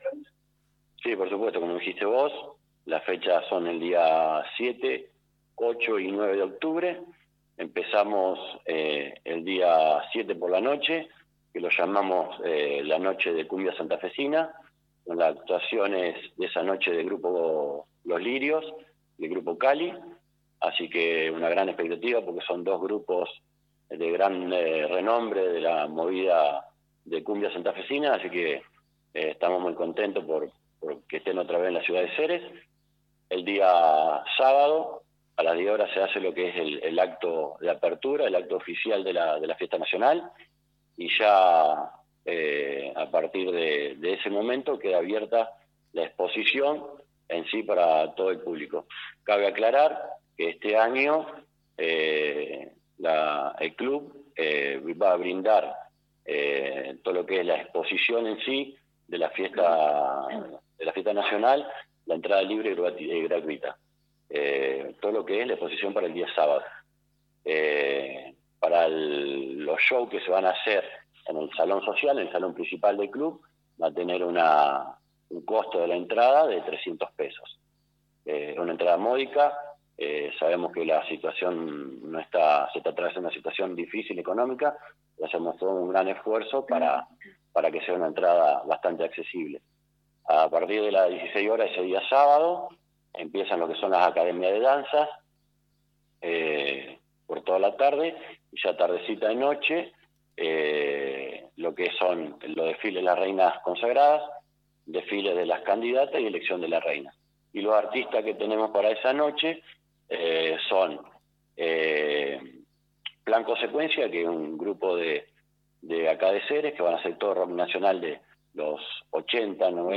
En un encuentro con los medios de prensa, el Club Central Argentino Olímpico dio todos los detalles de la 51° edición de la Fiesta Nacional de Zapallo, prevista para los días 7, 8 y 9 de octubre.